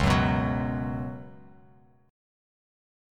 C#7sus4 Chord
Listen to C#7sus4 strummed